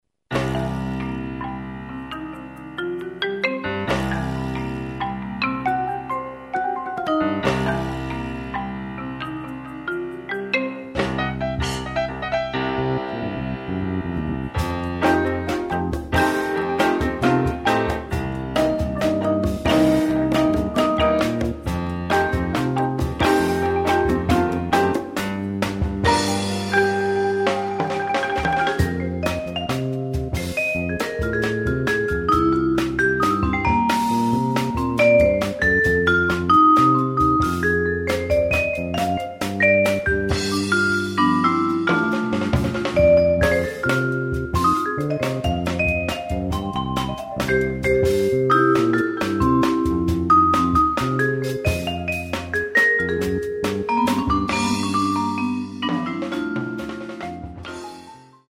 Genre: Percussion Ensemble
# of Players: 9
Vibraphone (3-octave)
Marimba 1 (4-octave)
Auxiliary Percussion (vibraslap, cabasa, tambourine)
Congas
Timpani (4 drums)
Piano
Electric Bass
Drum Set